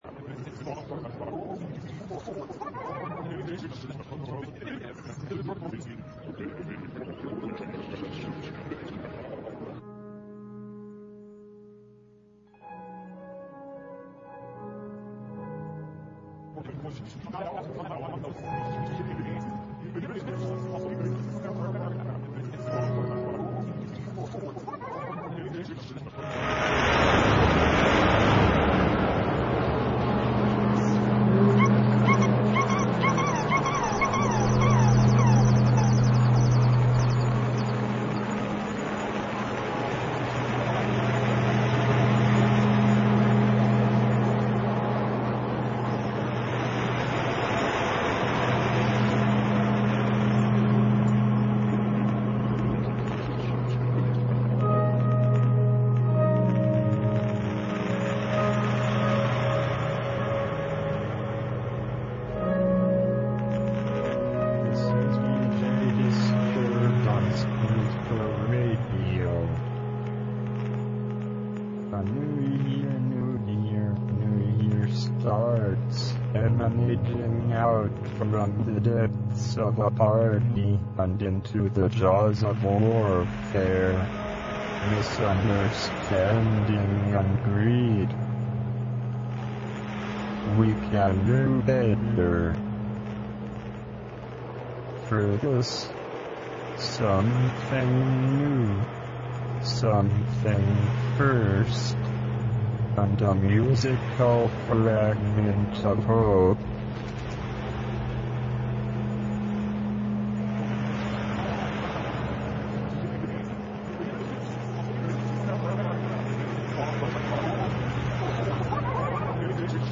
Live show every Thursday at 3 p.m. from Squidco is...